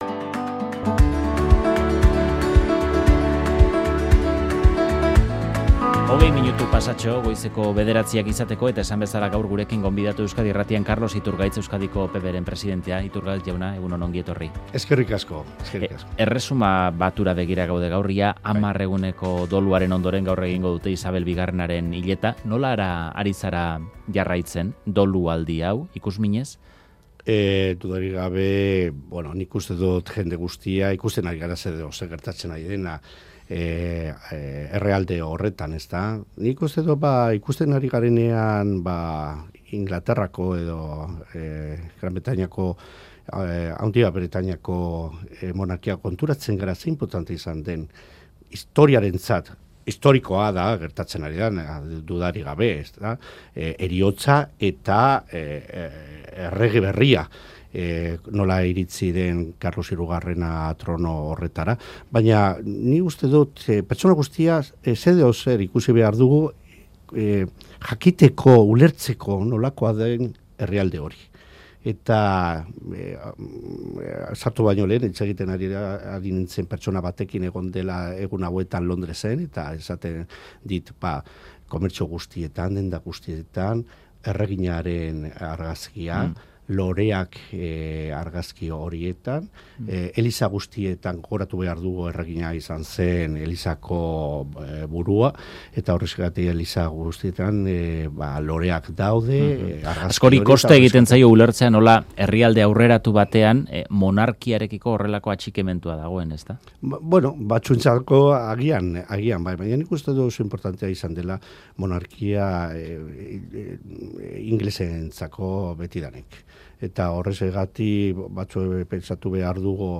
Audioa: Alderdi popularra EAJrekin hitz egiteko prest dagoela dio Carlos Iturgaiz Euskadiko PPren idazkari nagusiak, baina hitzordua egiteko urratsa ematea EAJri dagokiola azaldu du Euskadi Irratiko "Faktoria" saioan.